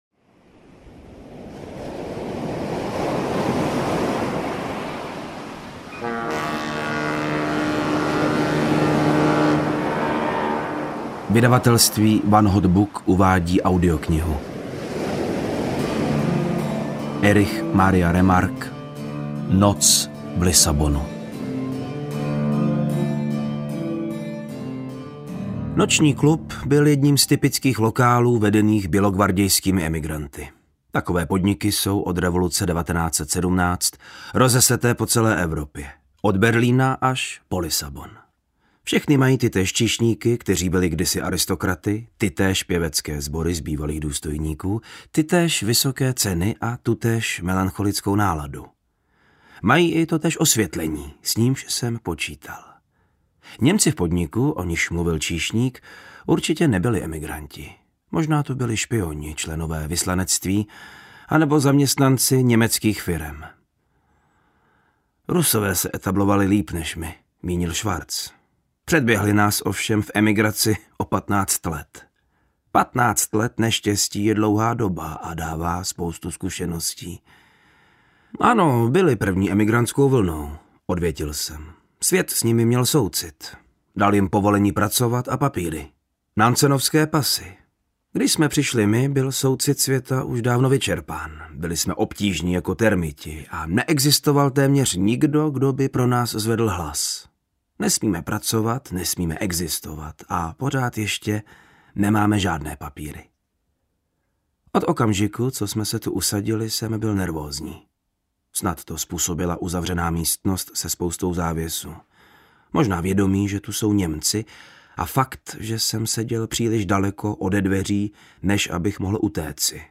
Noc v Lisabonu audiokniha
Ukázka z knihy
• InterpretVáclav Neužil